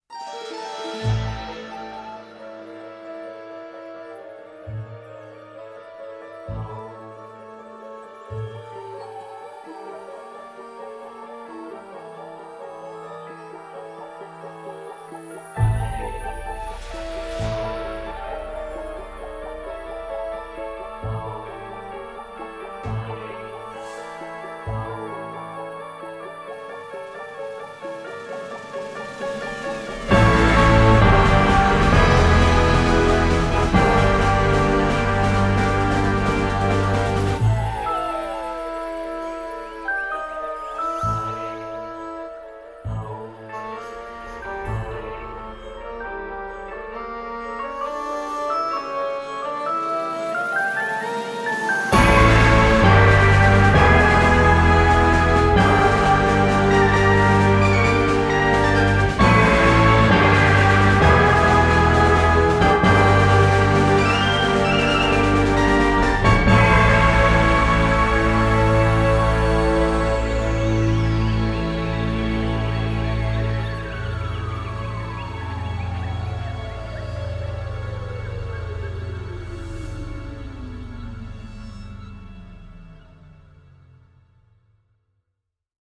Genre: Electropop